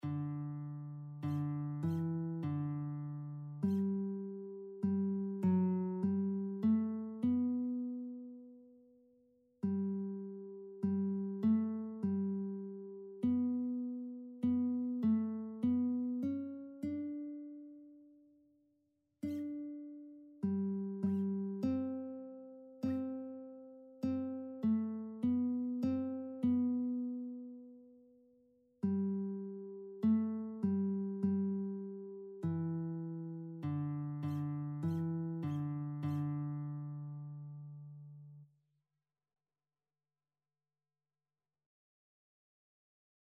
Christian Christian Lead Sheets Sheet Music Savior, Again to Your Dear Name We Raise
G major (Sounding Pitch) (View more G major Music for Lead Sheets )
2/2 (View more 2/2 Music)
Classical (View more Classical Lead Sheets Music)